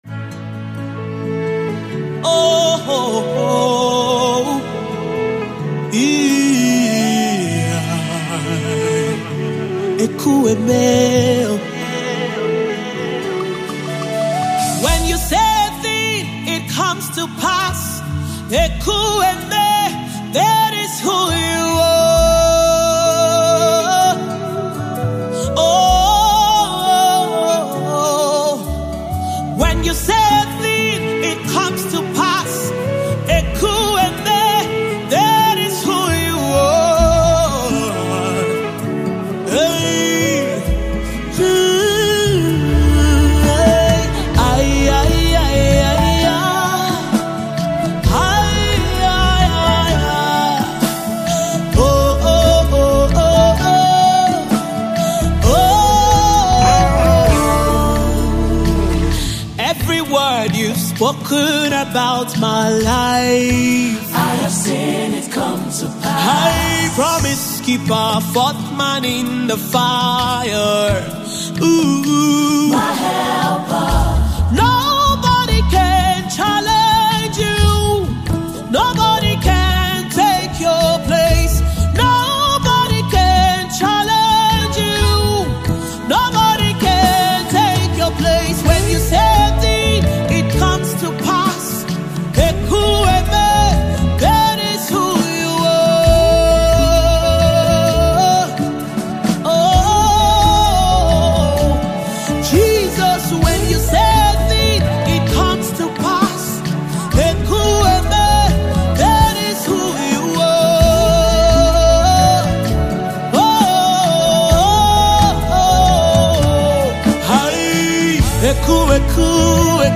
soul-stirring gospel anthem
powerful vocals
Gospel Songs